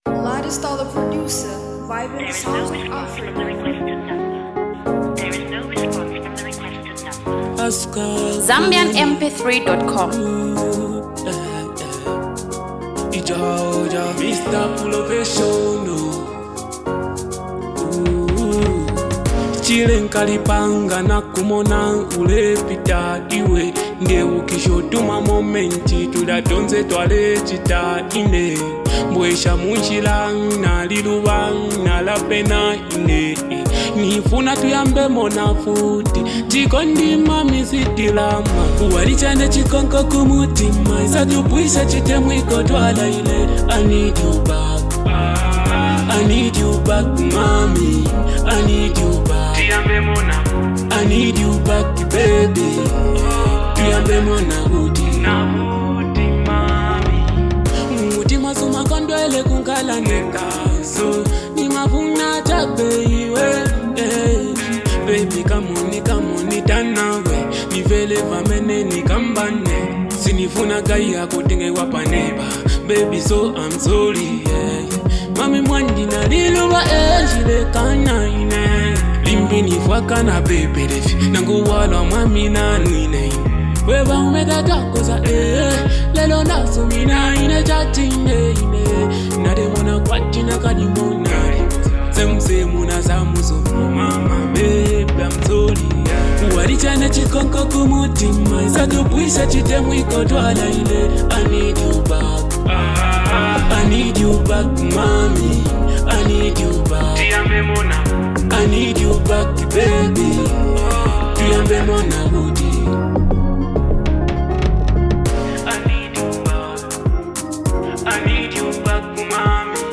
The song is filled with a dope vibe.